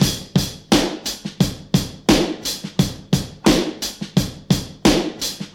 87 Bpm Drum Loop C Key.wav
Free breakbeat - kick tuned to the C note. Loudest frequency: 1878Hz
87-bpm-drum-loop-c-key-93y.ogg